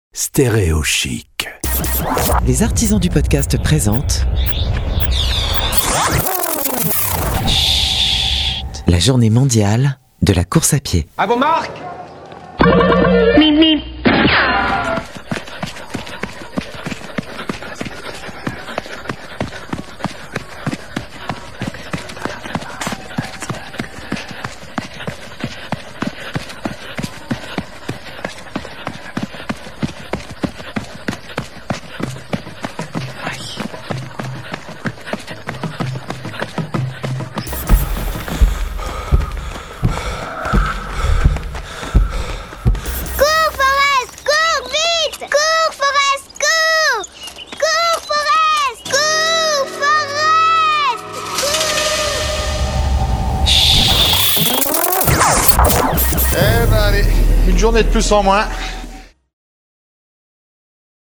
A l'occasion de la Journée Mondiale de la course à pied, le 3 Juin 2021, voici une immersion de 60 secondes avec Schhhhhht produit par les Artisans du Podcast.